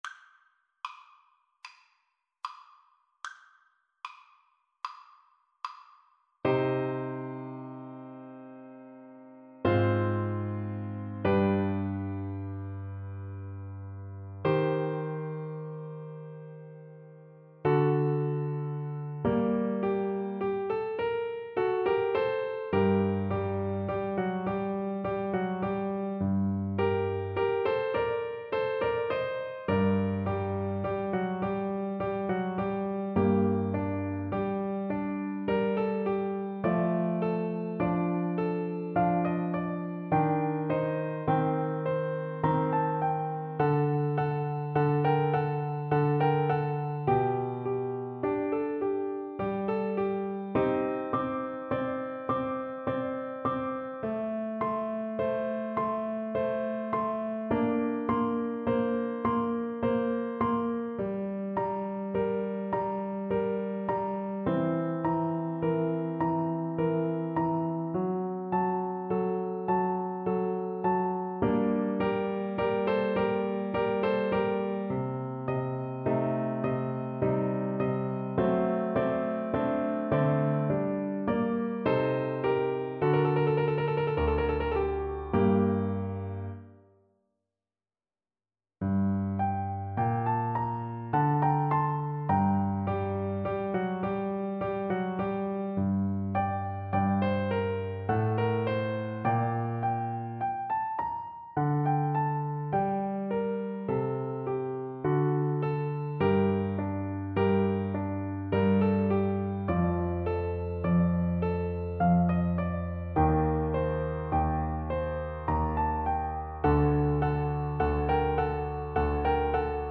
Piano Playalong MP3